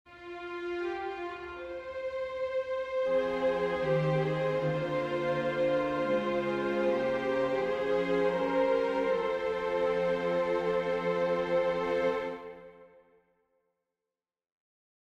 Key written in: F Minor
How many parts: 4
Type: Barbershop
All Parts mix: